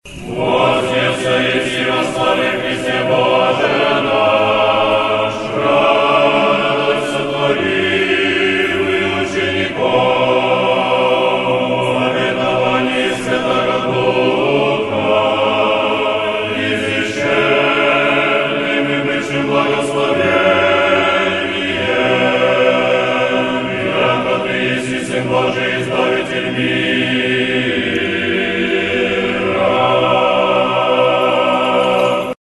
Tropar-voznesenie_gospodne.ogg  (размер файла: 496 Кб, MIME-тип: application/ogg ) Тропарь Вознесения Господня История файла Нажмите на дату/время, чтобы просмотреть, как тогда выглядел файл.
Tropar-voznesenie_gospodne.ogg